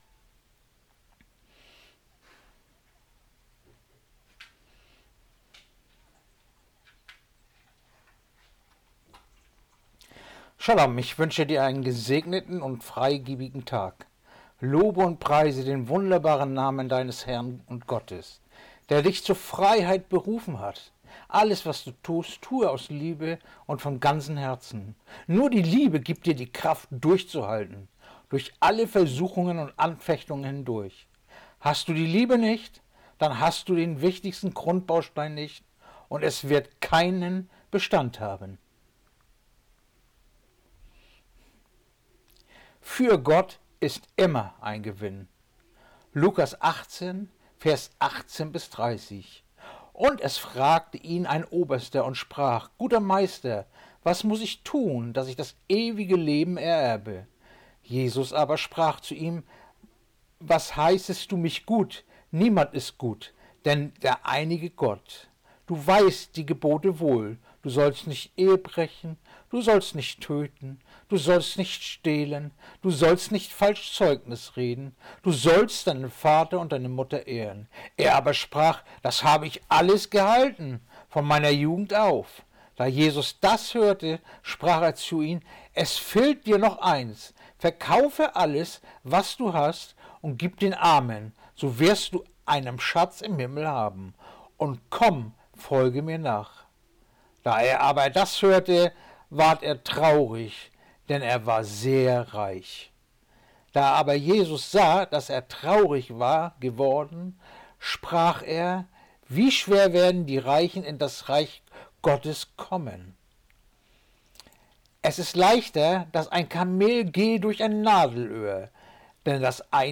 Andacht-vom-04.-November-Lukas-18-18-30